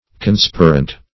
Search Result for " conspirant" : The Collaborative International Dictionary of English v.0.48: Conspirant \Con*spir"ant\, a. [L. conspirans, p. pr. of conspirare: cf. F. conspirant.]